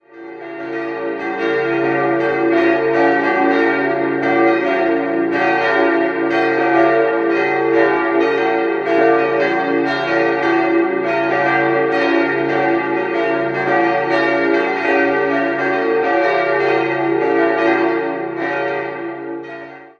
Idealquartett: d'-f'-g'-b' Die drei größeren Glocken wurden 1950 von Johann Hahn in Landshut gegossen, über die kleine liegen keine genaueren Informationen vor.